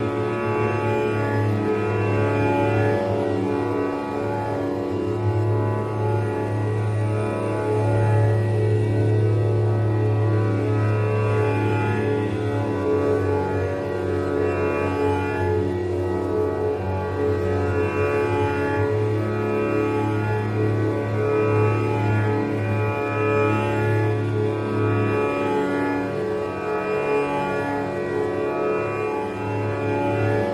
Sitar and Eerie Voice